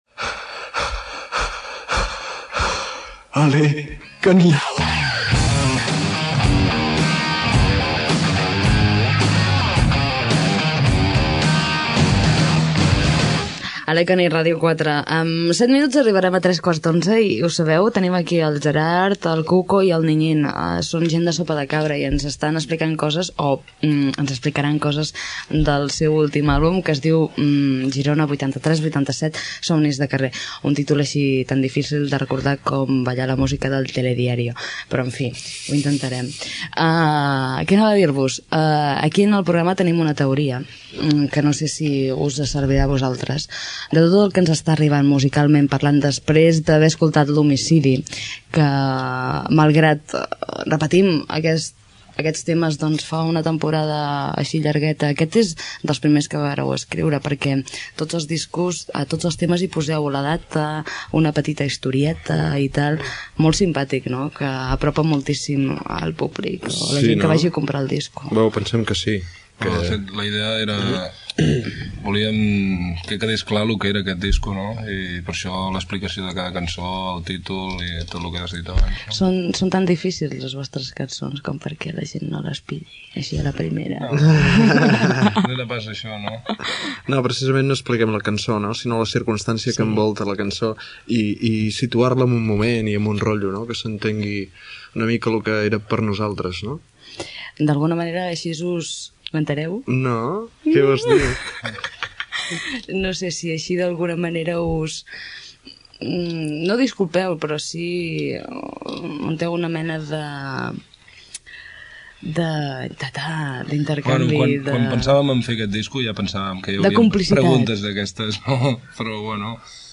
Indicatiu del programa. Entrevista als integrants del grup de Sopa de Cabra que presenten el disc "Girona 83-87: Somnis de carrer"